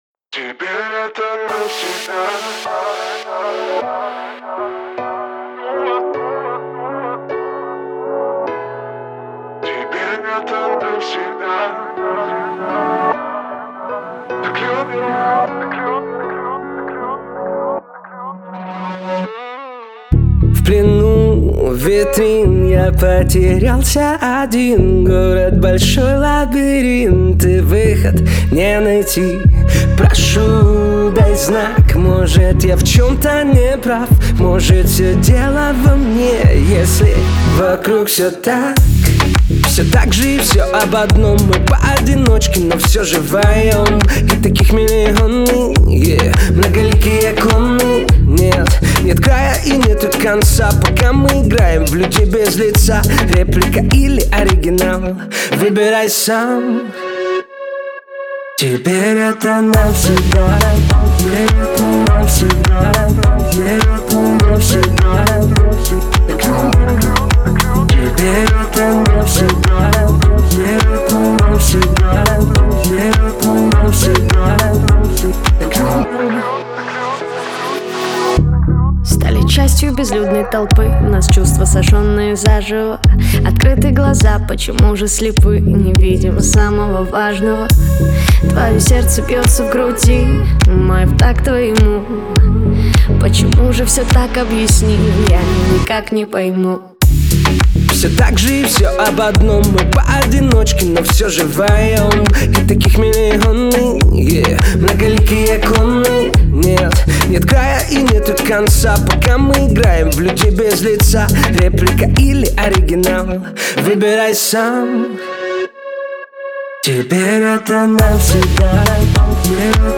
отличается мелодичными синтезаторами и ритмичными битами